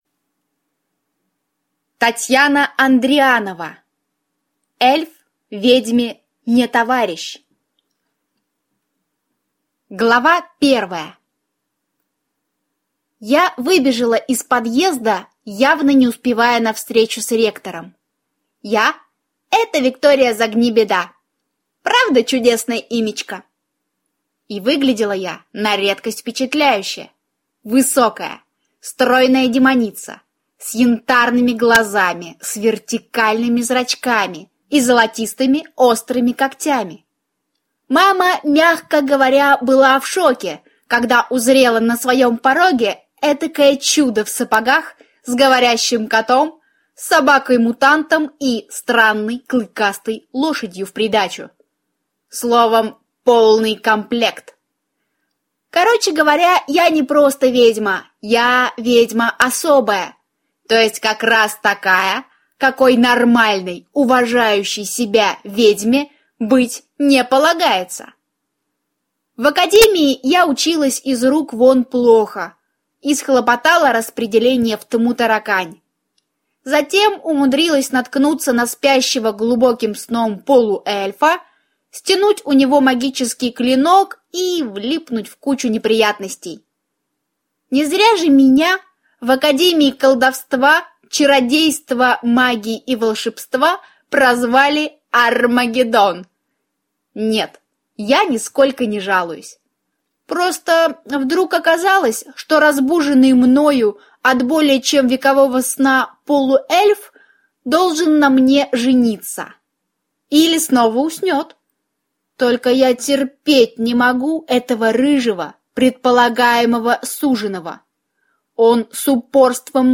Аудиокнига Эльф ведьме не товарищ | Библиотека аудиокниг